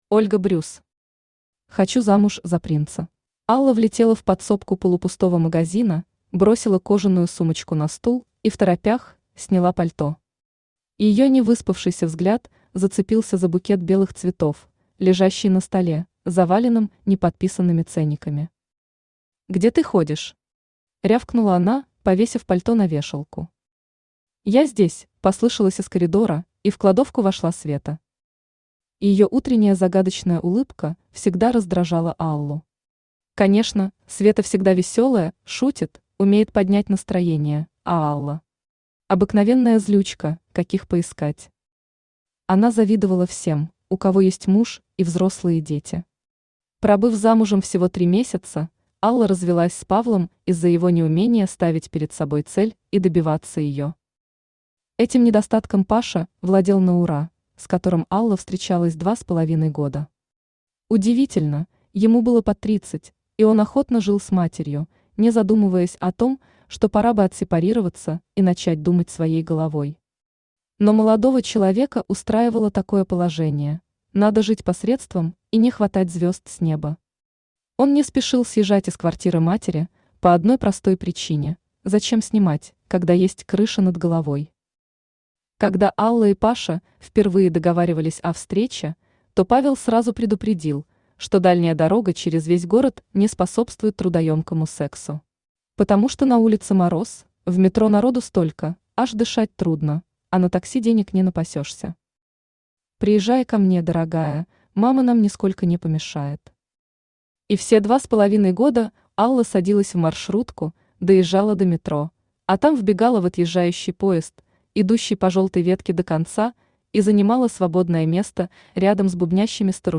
Aудиокнига Хочу замуж за принца Автор Ольга Брюс Читает аудиокнигу Авточтец ЛитРес.